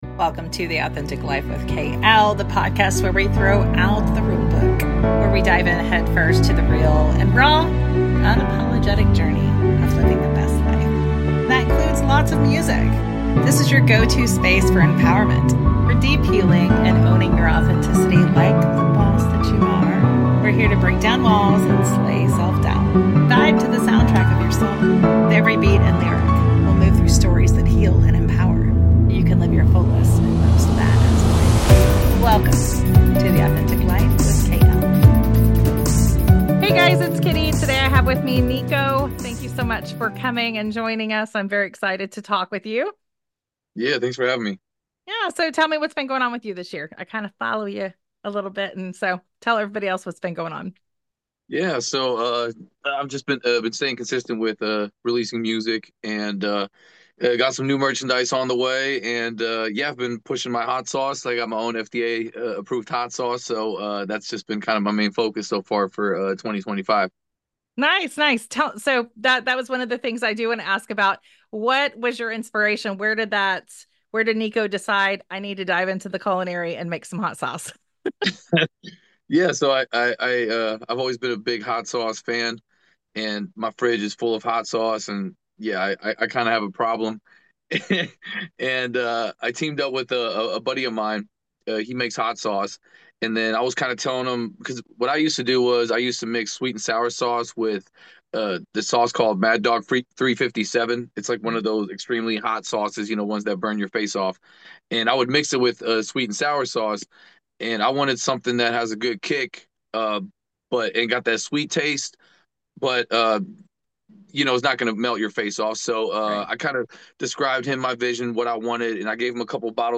We’ll dive into his creative process, his passion for storytelling through music, and of course, maybe even his favorite hot sauce flavors. Get ready for a fiery conversation!